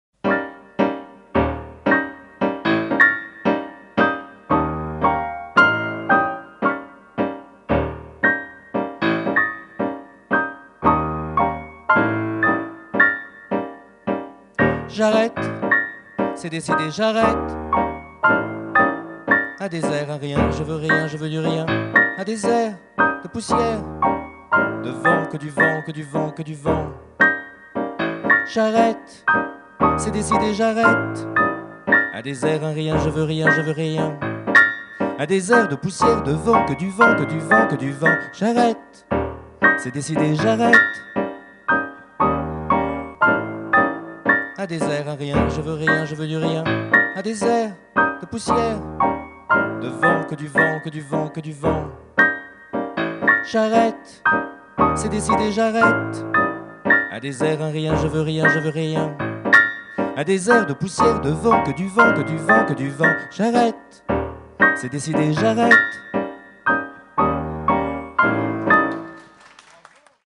Chanson écrite lors d’un atelier Écriture & musique